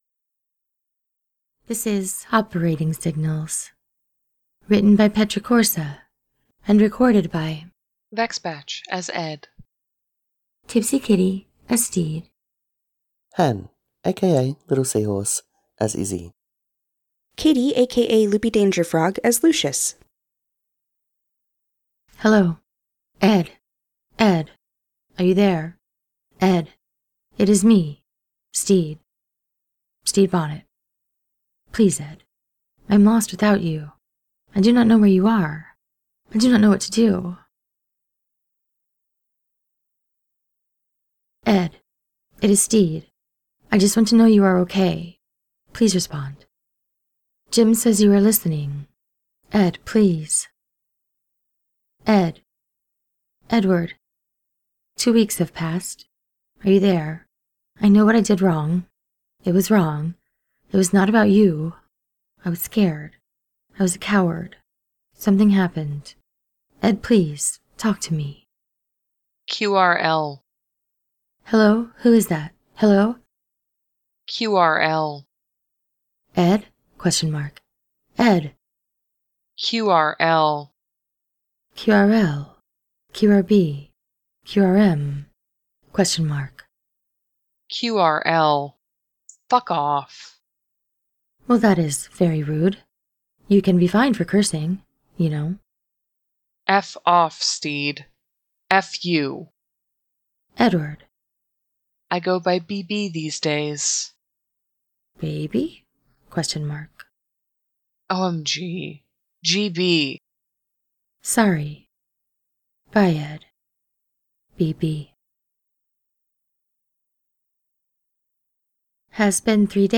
collaboration|ensemble
no music/sfx: